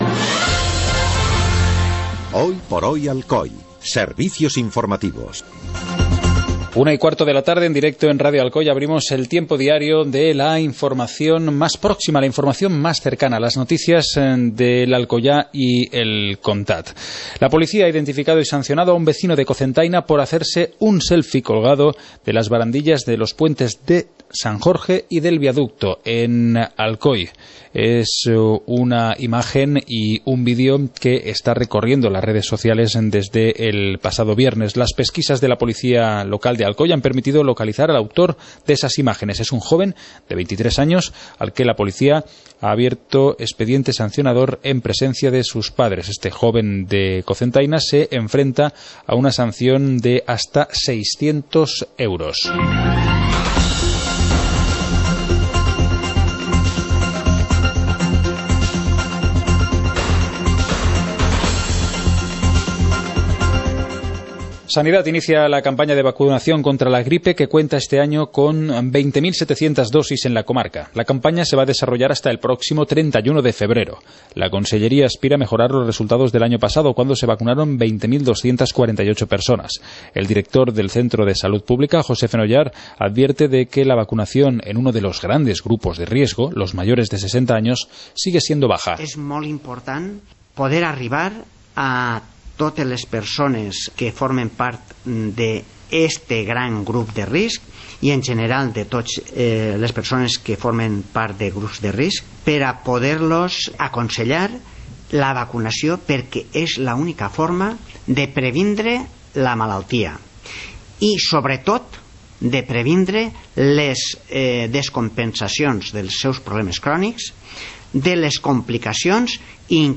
Informativo comarcal - martes, 20 de octubre de 2015